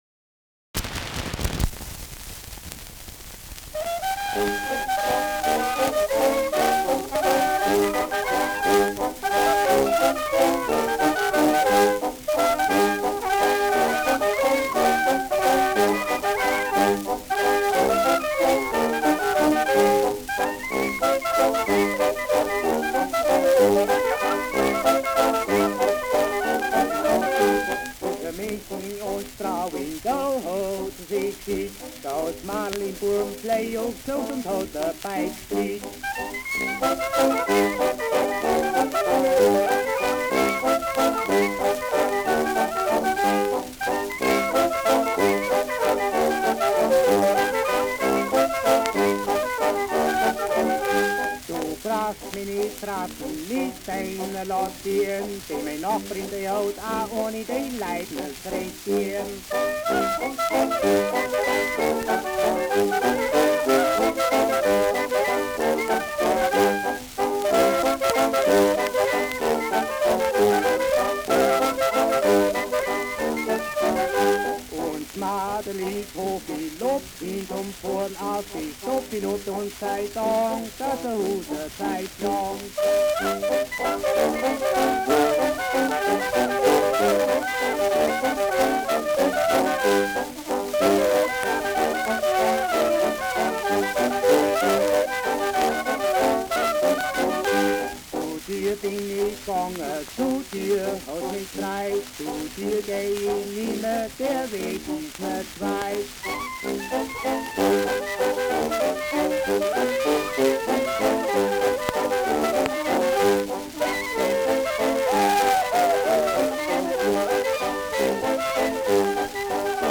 Zwischen München und Straubing : Ländler mit Gesang
Schellackplatte
Stärkeres Grundrauschen : Gelegentlich leichtes bis stärkeres Knacken
Kapelle Die Alten, Alfeld (Interpretation)